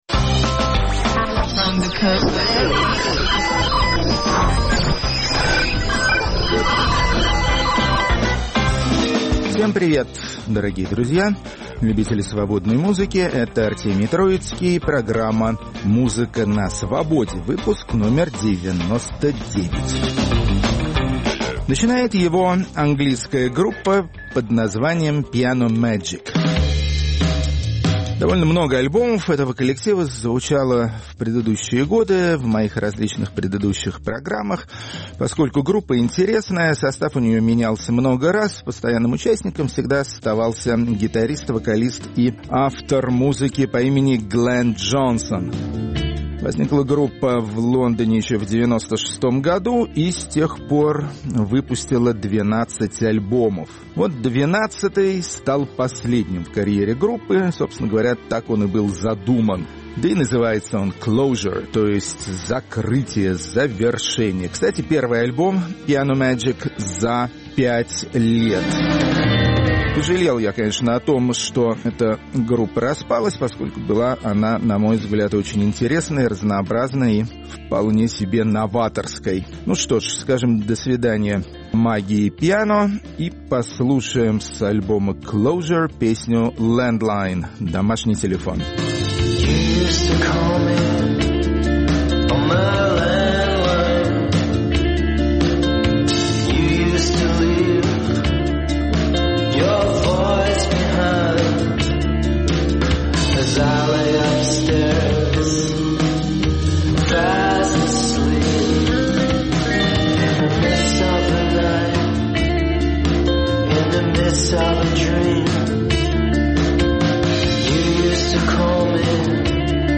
российские группы альтернативного рока.